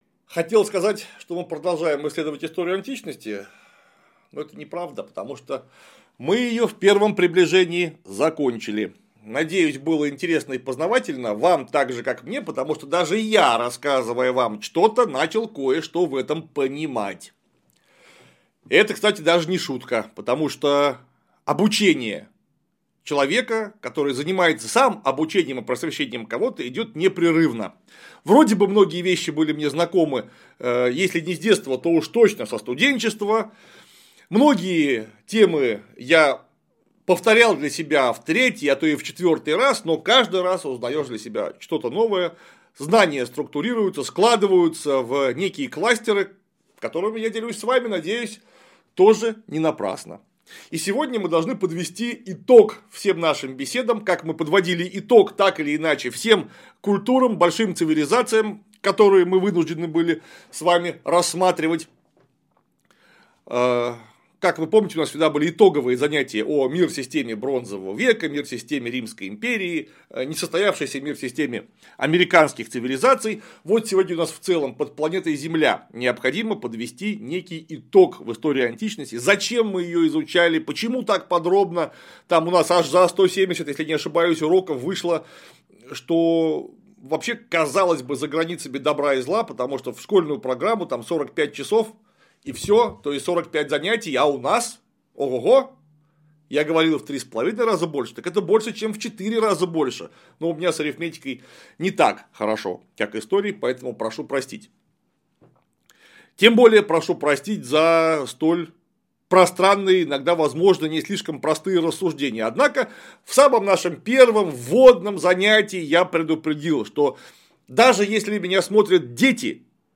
Урок 155. Восприятие истории Древней Кореи.